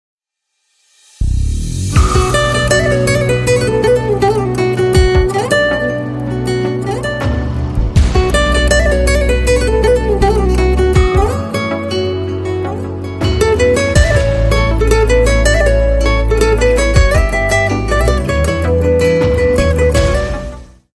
آهنگ زنگ دخترونه غمگین بی کلام